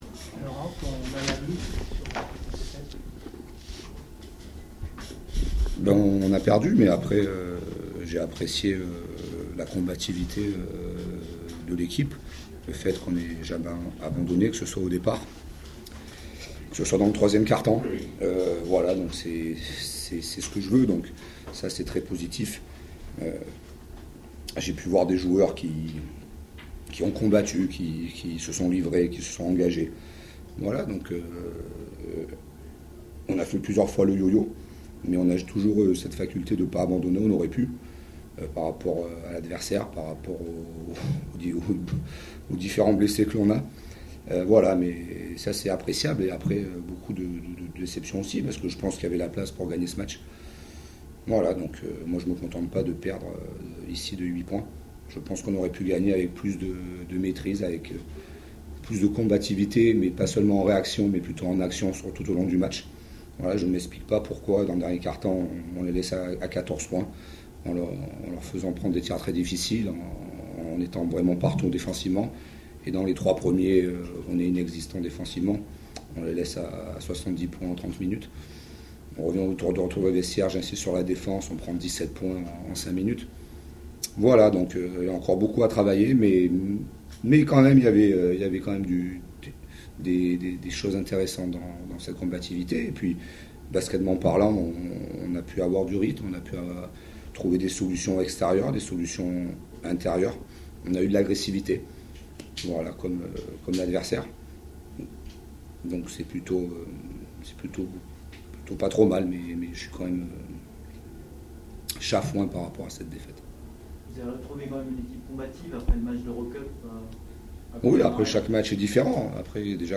Betclic Elite #8 – Les réactions - JL Bourg Basket